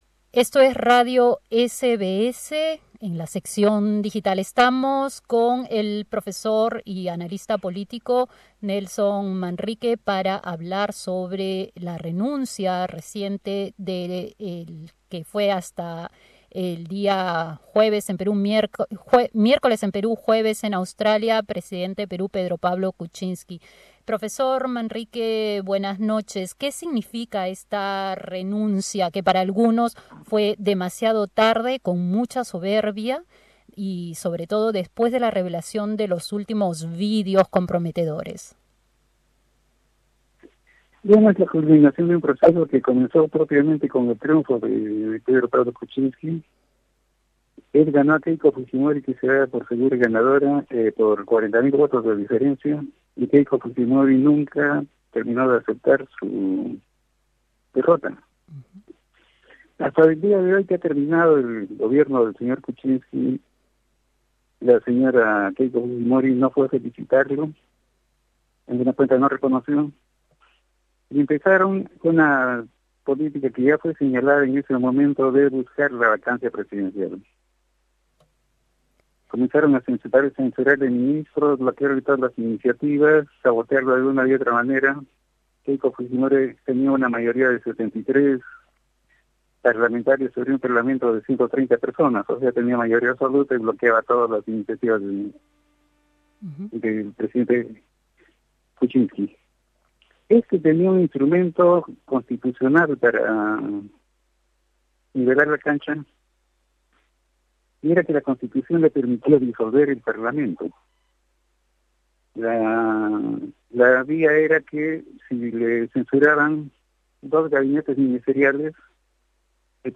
¿Quién gana, quién pierde, qué escenarios son posibles política y judicialmente? Escucha toda la entrevista